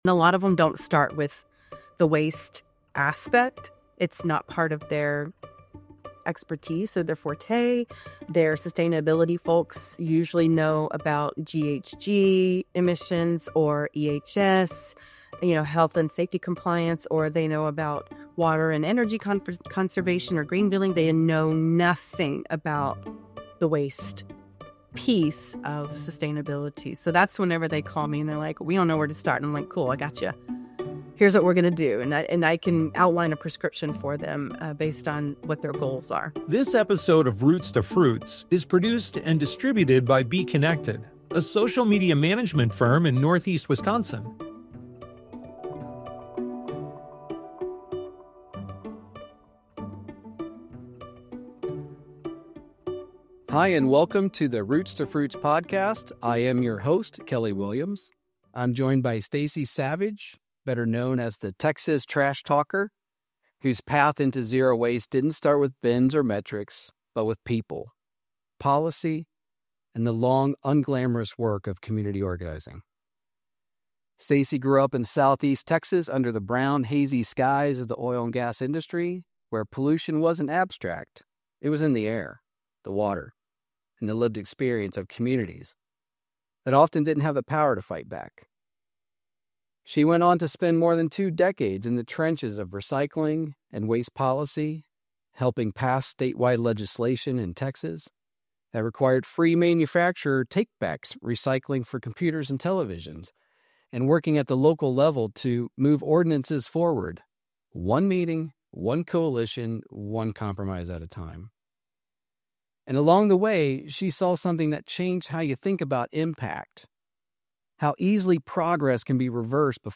The conversation explores how environmental progress is often slow, fragile, and easily reversed, especially within waste and recycling policy.